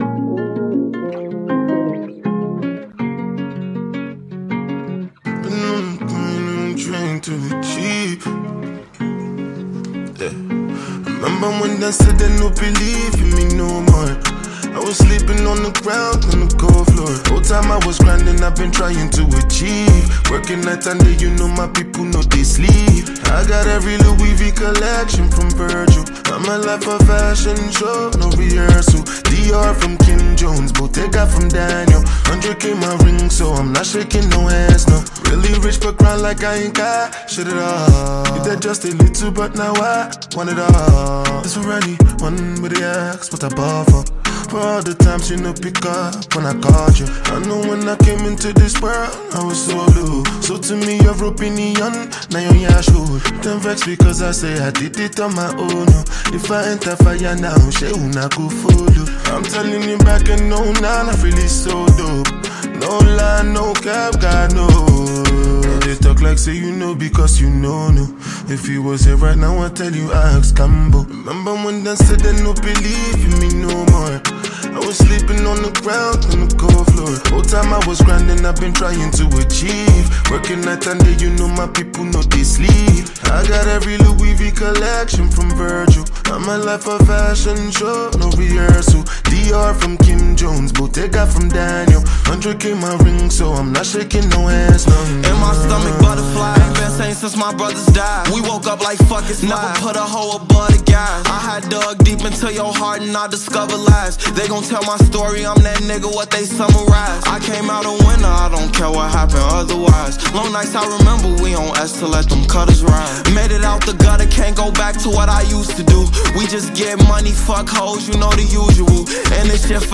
presents a soul-stirring release